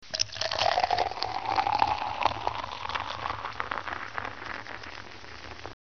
Einschenken 1 98 KB 1572 Sound abspielen!
einschenken1.mp3